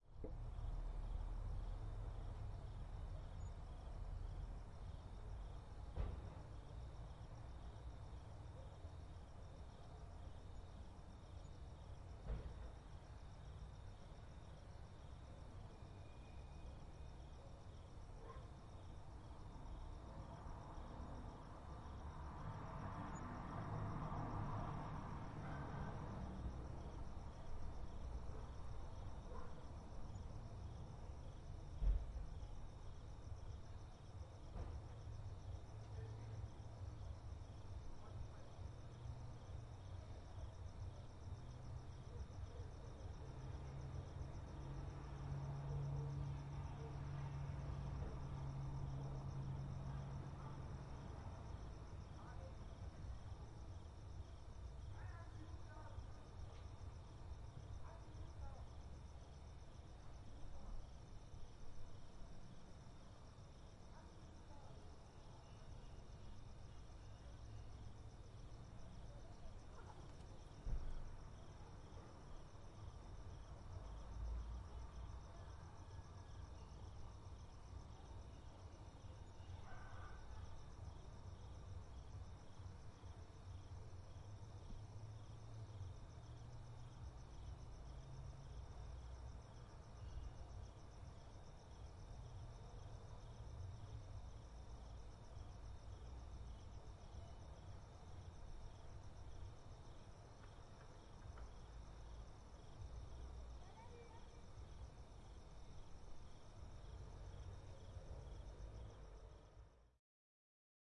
户外氛围之夜
描述：在比勒陀利亚郊区的夜间氛围。在蟋蟀的声音之上，每隔一段时间，一辆汽车就会从远处开出风大的道路，还有几只狗吠。以立体声录制。
Tag: OWI 背景声 音景 大气 气氛 环境 背景 ATMOS 一般噪声 气氛